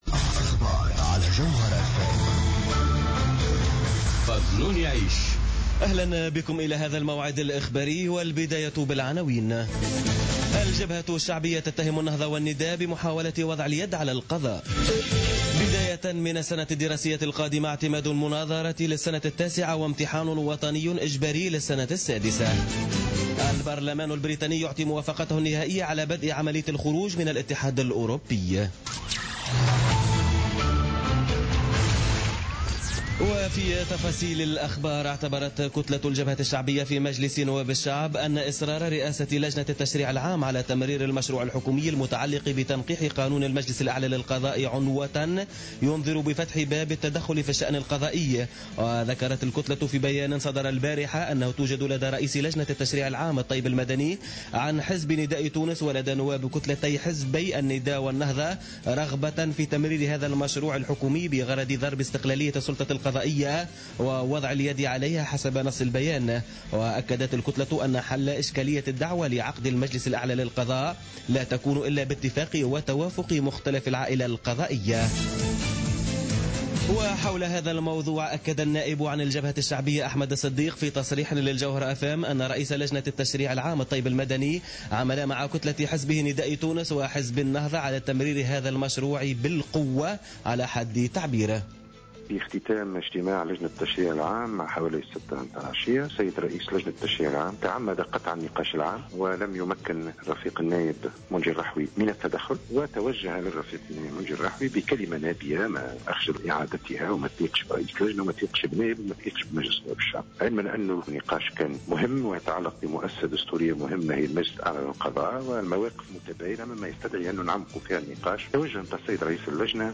نشرة أخبار منتصف الليل ليوم الثلاثاء 14 مارس 2017